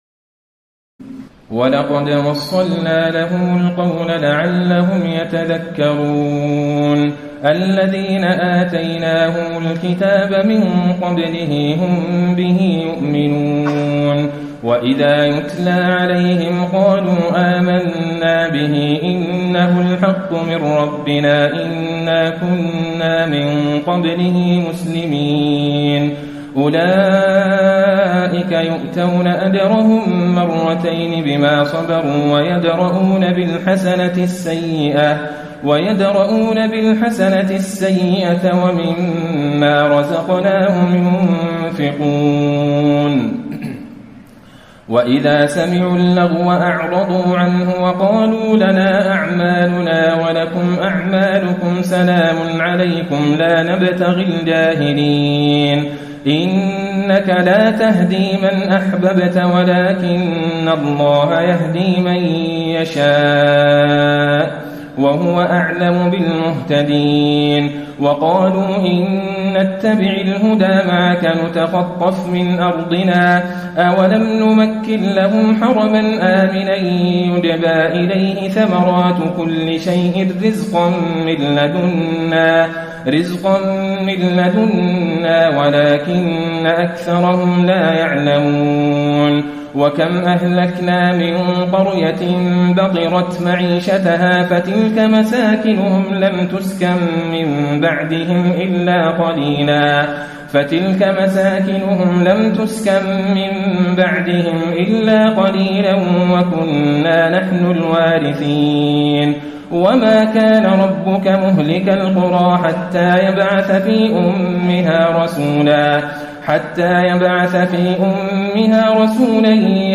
تراويح الليلة التاسعة عشر رمضان 1434هـ من سورتي القصص (51-88) والعنكبوت (1-45) Taraweeh 19 st night Ramadan 1434H from Surah Al-Qasas and Al-Ankaboot > تراويح الحرم النبوي عام 1434 🕌 > التراويح - تلاوات الحرمين